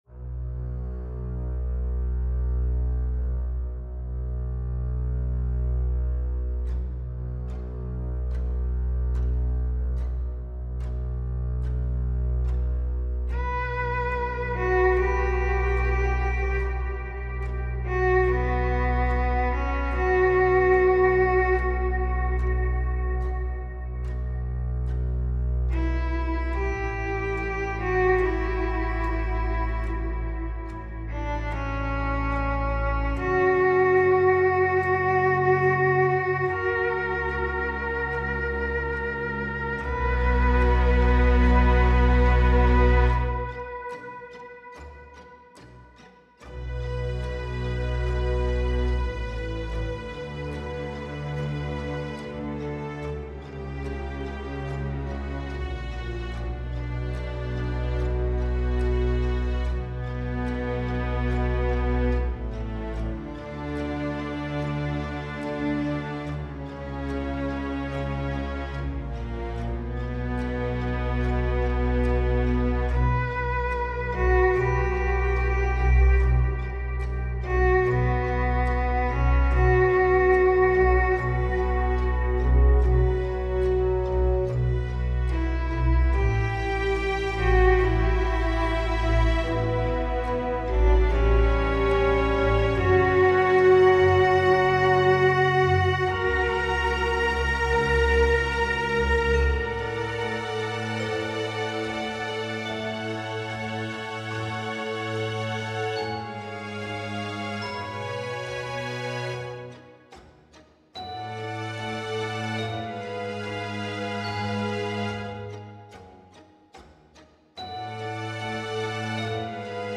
- Orchestral and Large Ensemble - Young Composers Music Forum